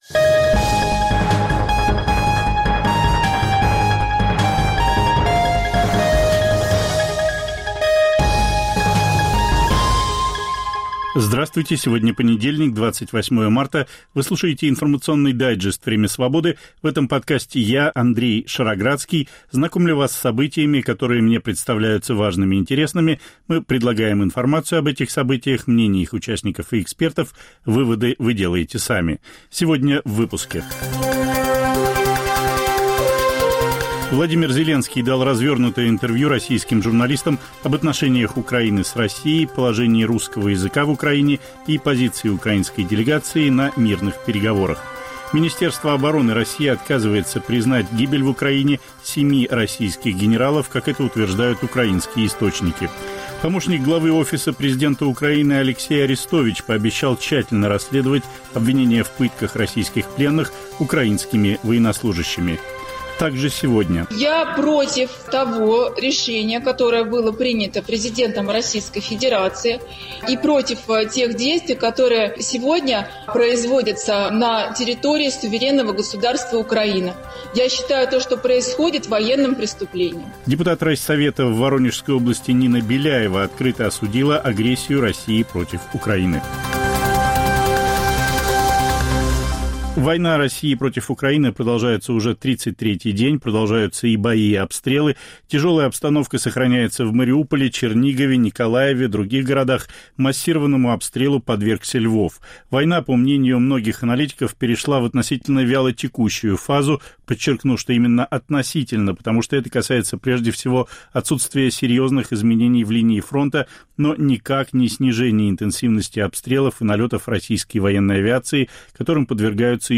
Время Свободы: "Демилитаризацию", "денацификацию" мы даже не обсуждаем. Интервью Зеленского российским журналистам